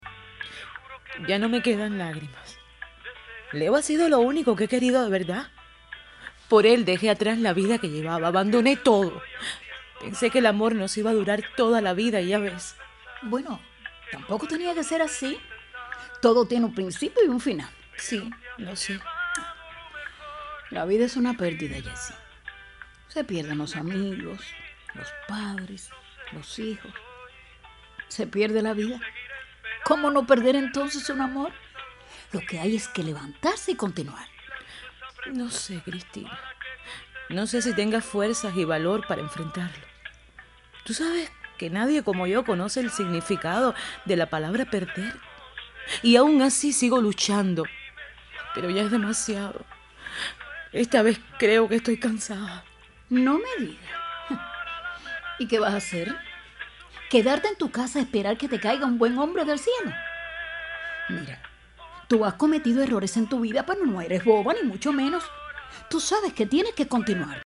DRAMATIZADO.mp3